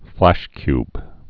(flăshkyb)